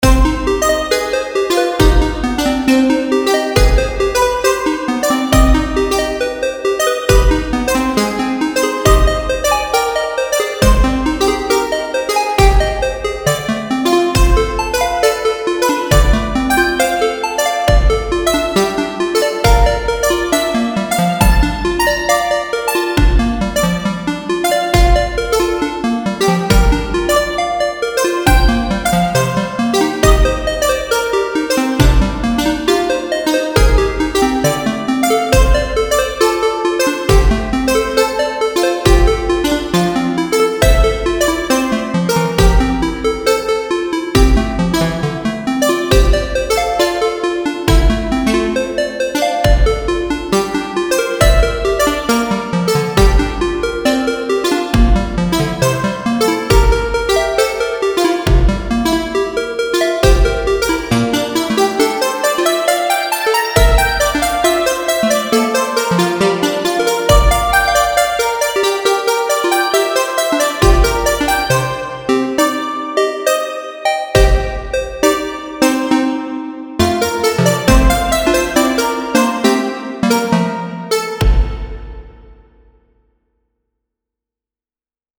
Dream(Electronic)_0.mp3